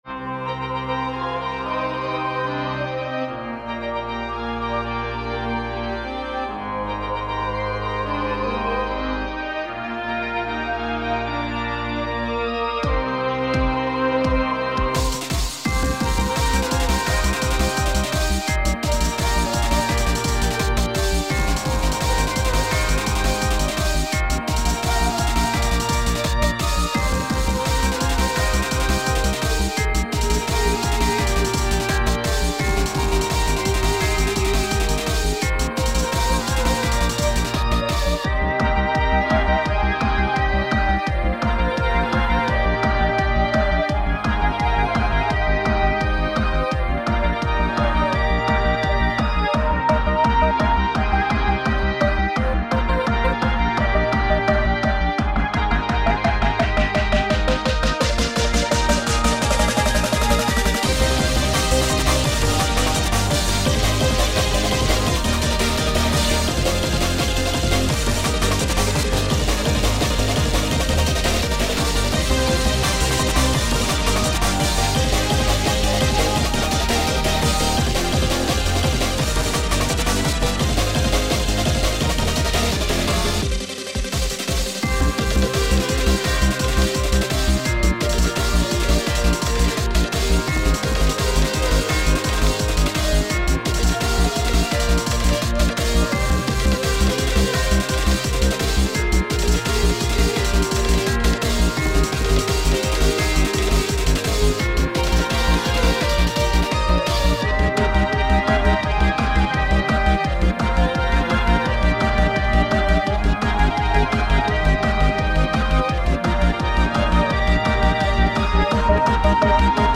壮大で美しいサウンドを目指しつつ、
盛り上がりの部分では、かっこよく疾走感のある曲にしました。